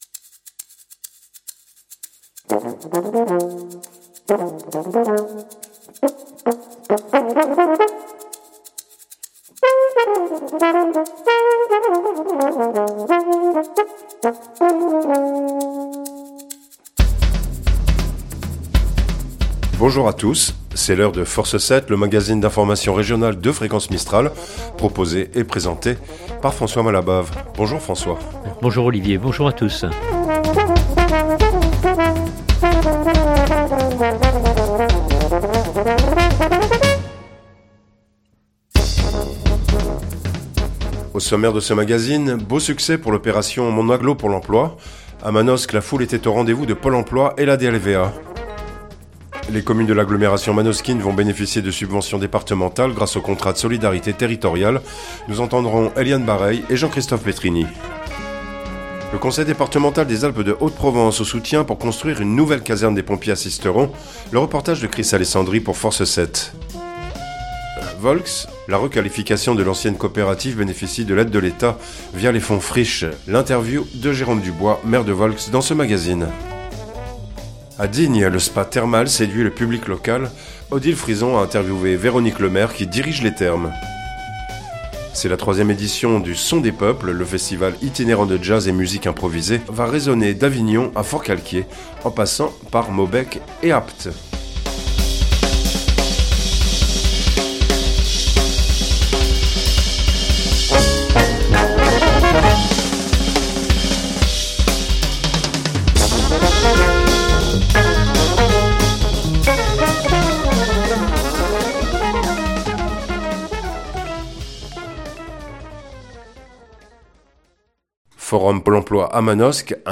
un magazine d’information régional
L’interview de Jérôme Dubois, maire de Volx dans ce magazine.